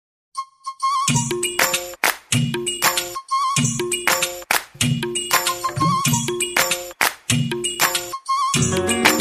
Flute Ringtones